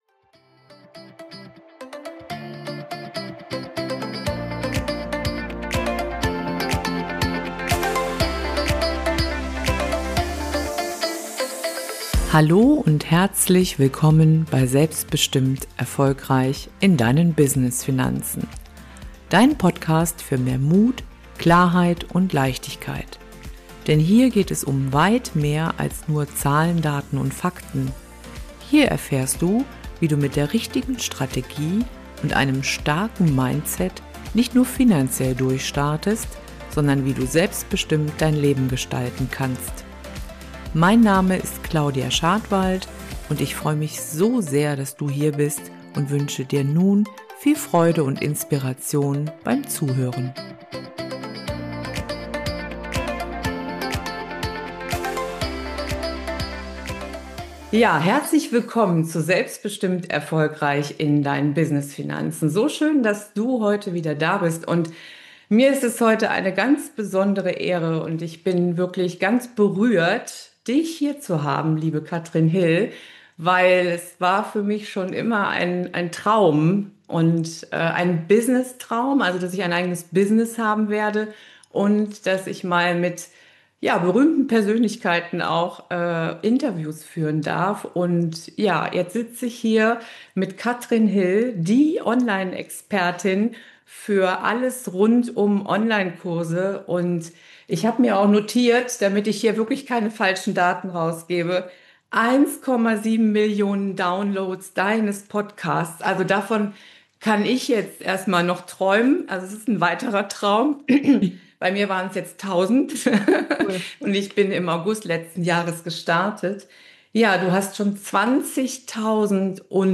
Think big! Wenn du etwas willst, dann schaffst du's auch - Interview